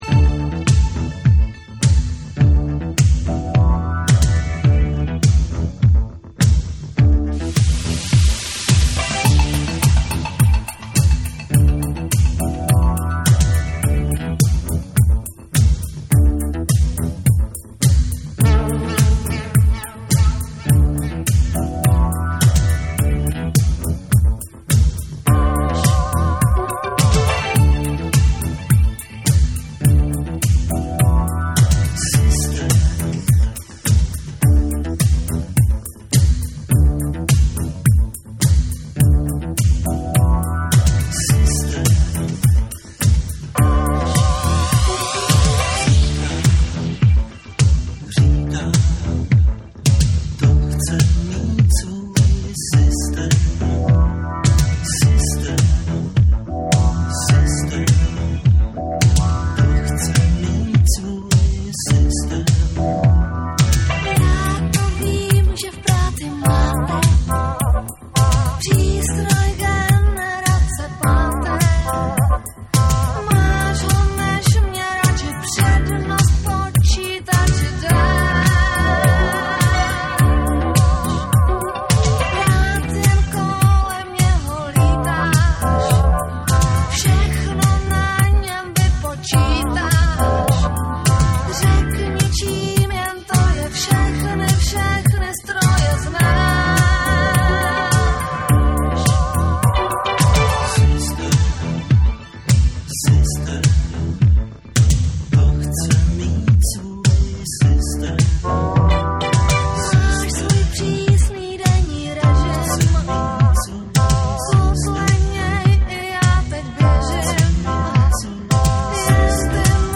乱れ打つビートを絡めたスローモーなトラックに神秘的なヴォーカルが一体となるコズミック・ディスコの1(SAMPLE 1)。
TECHNO & HOUSE / RE-EDIT / MASH UP